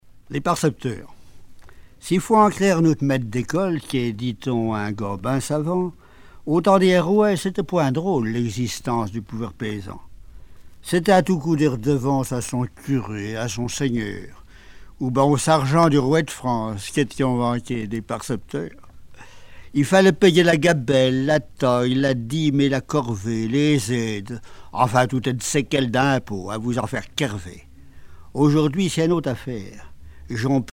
Langue Angevin
Genre poésie
Catégorie Récit